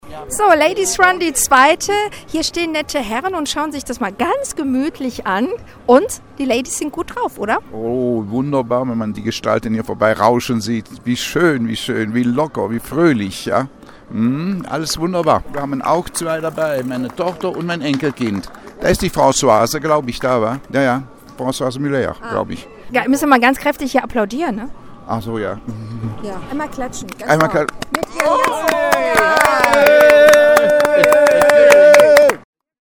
Unter dem Motto BeActive ging gestern die Europäische Woche des Sports an den Start. Auftaktveranstaltung war der Ladies Run in Eupens Innenstatdt.